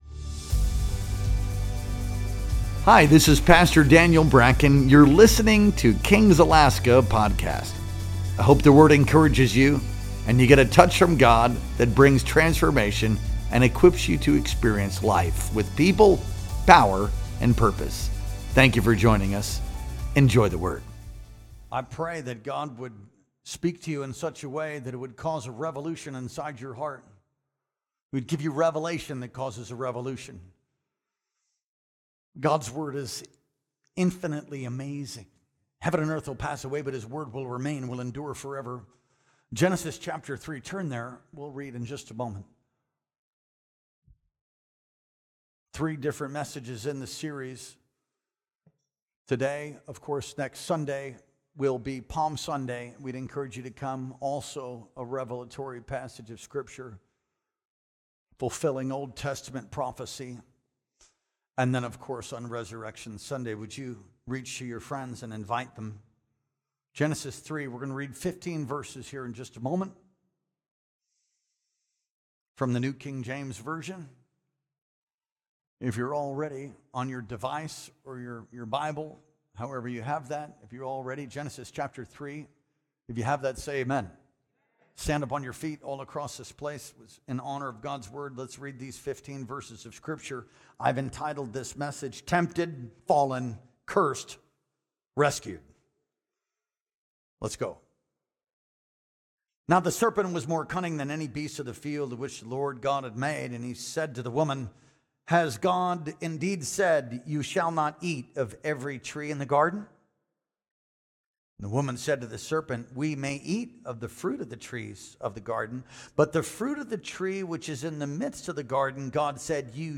Our Sunday Worship Experience streamed live on April 6th, 2025.